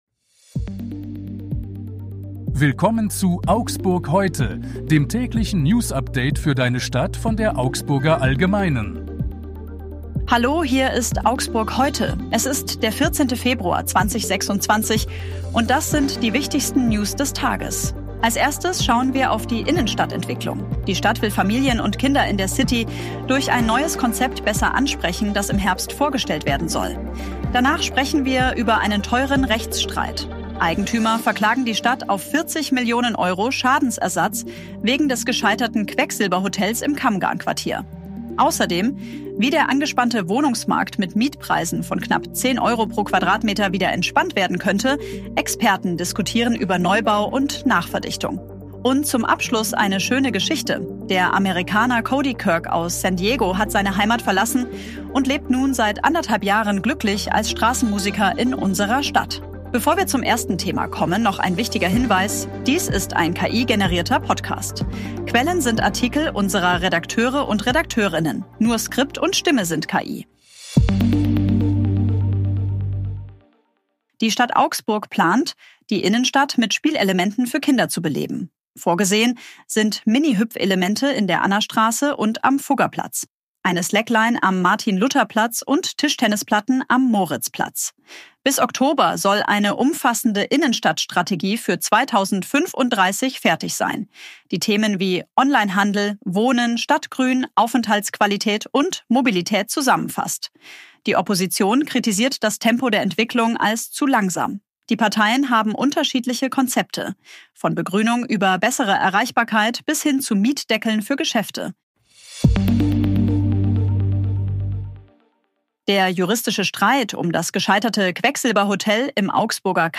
Nur Skript und